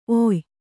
♪ oy